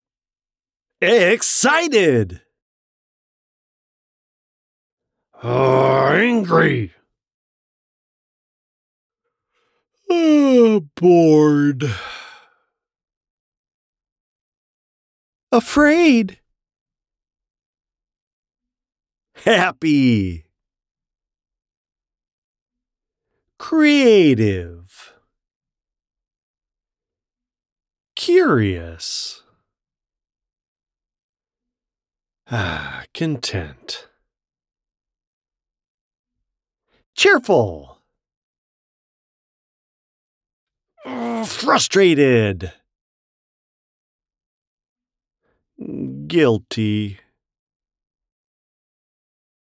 My voice is a rich baritone, a bit gravelly now that I'm in my mid-50s but still nice and strong, dignified but with lots of energy and expression, that is very well-suited to narrative deliveries for projects such as audiobooks, documentaries, explainers, and suitable broadcast-style advertisements.
Character / Cartoon
Children's Audiobook
Words that describe my voice are baritone, gravelly, expressive.